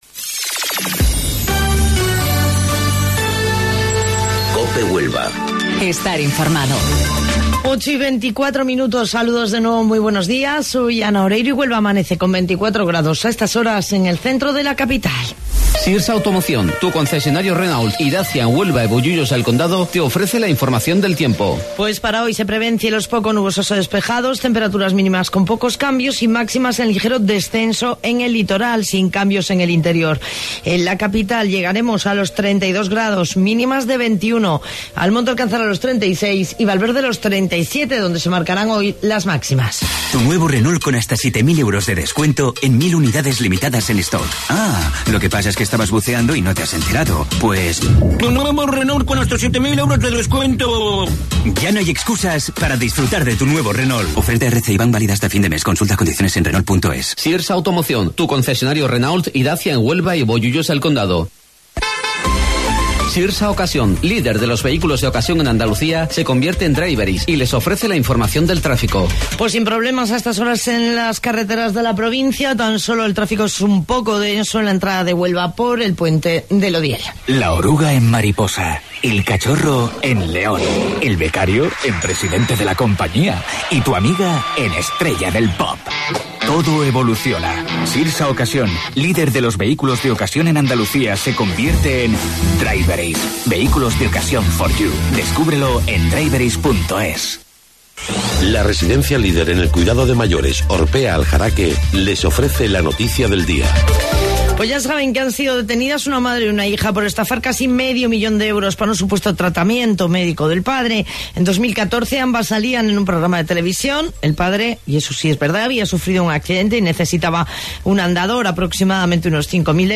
AUDIO: Informativo Local 08:25 del 24 de Julio